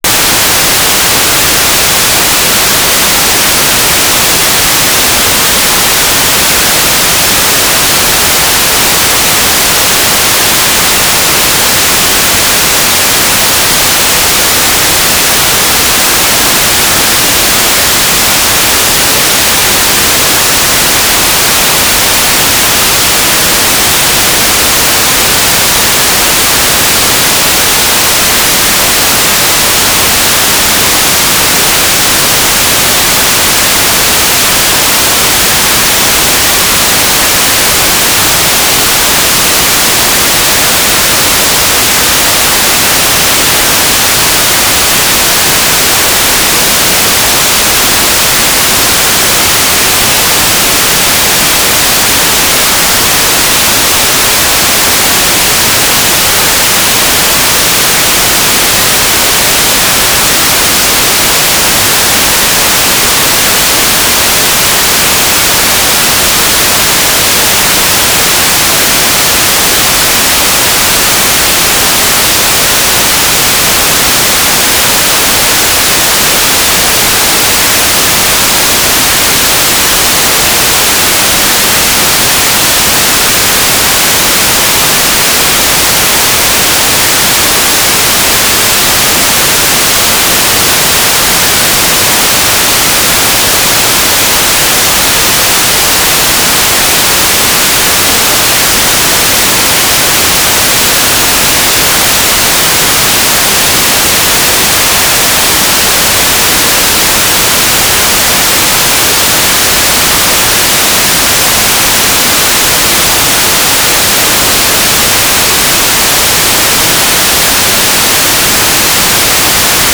"transmitter_description": "S-band telemetry",